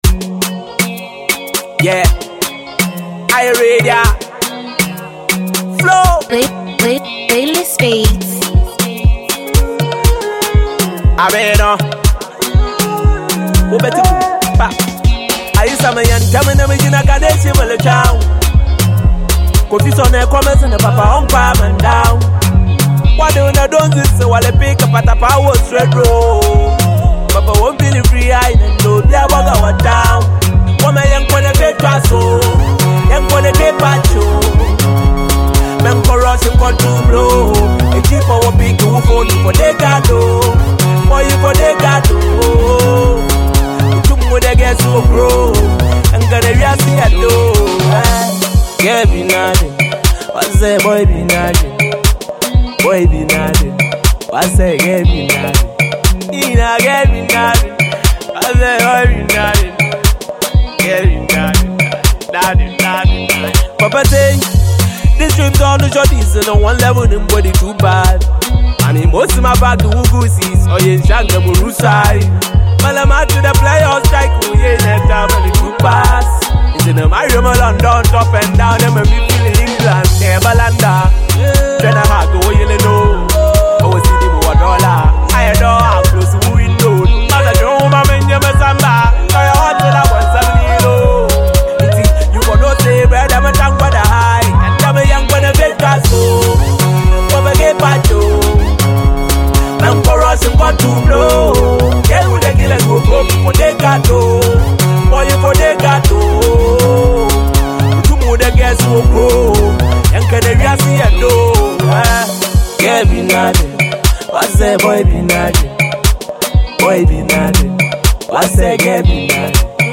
Award Winning Fante rapper
serves up a catchy single